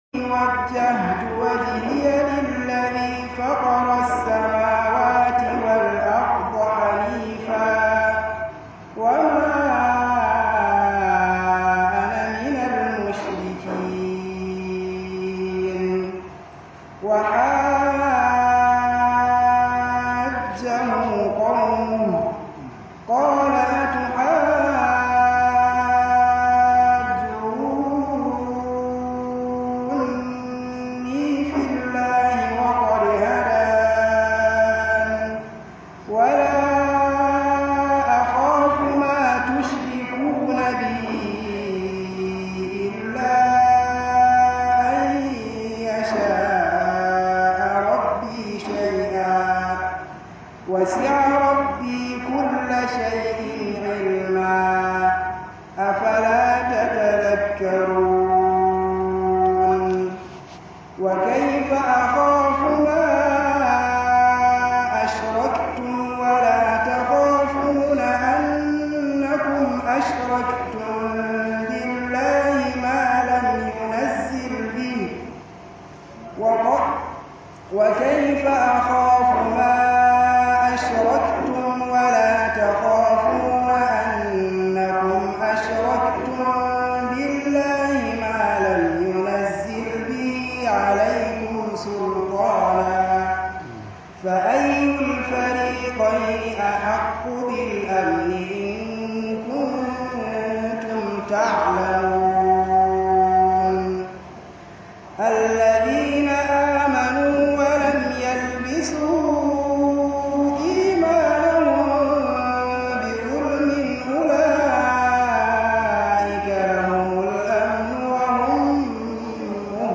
Wa'azin ATAP Bauchi